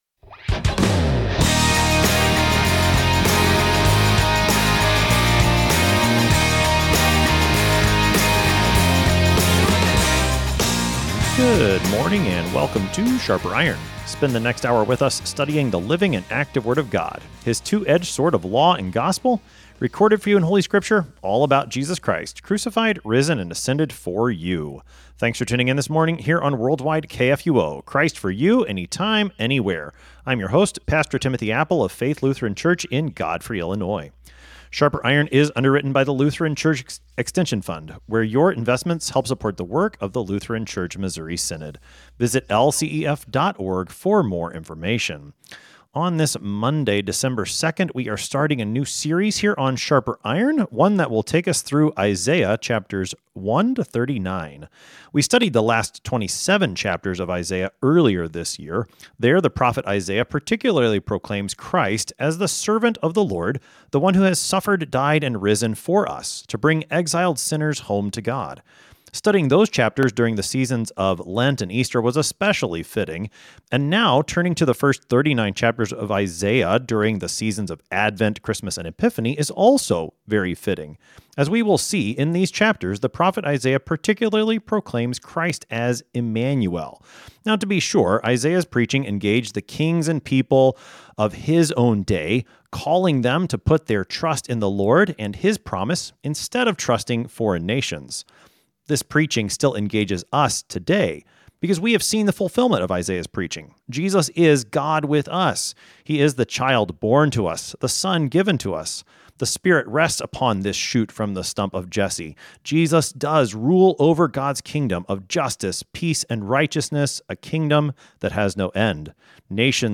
Two pastors engage with God’s Word to sharpen not only their own faith and knowledge, but the faith and knowledge of all who listen.